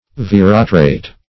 veratrate - definition of veratrate - synonyms, pronunciation, spelling from Free Dictionary Search Result for " veratrate" : The Collaborative International Dictionary of English v.0.48: Veratrate \Ve*ra"trate\, n. (Chem.)